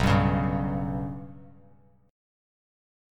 Bsus2#5 chord